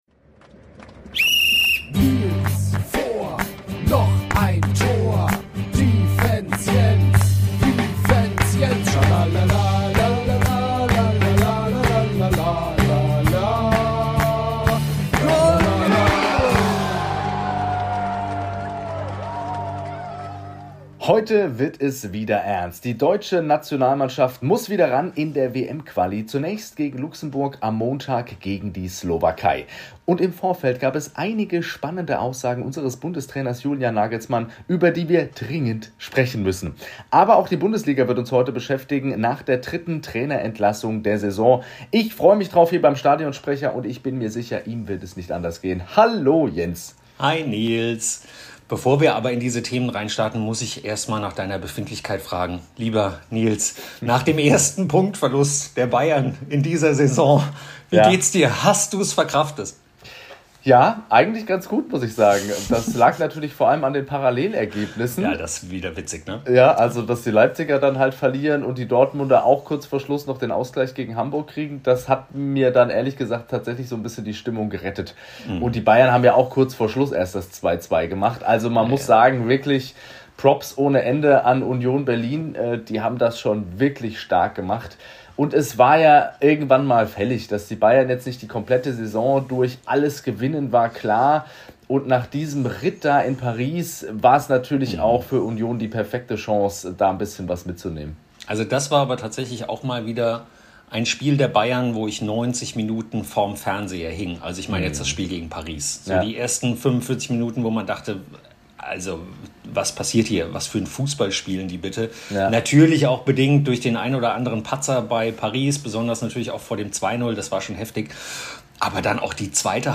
Ihr hört deshalb unsere Backup-Spuren.